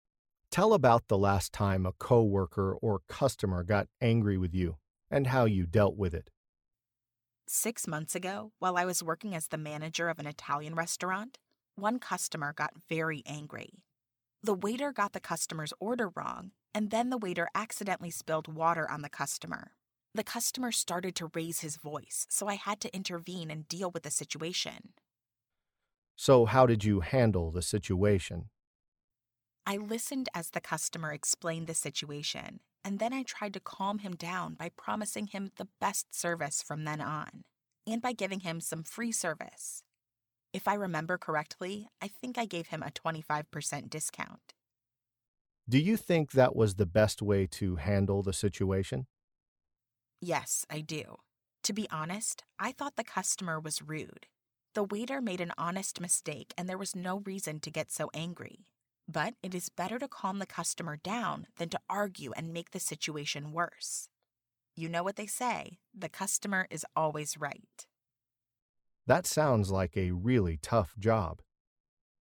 Learn different ways to answer the interview question 'Tell about the last time a co-worker or customer got angry with you and how you dealt with it.', listen to an example conversation, and study example sentences.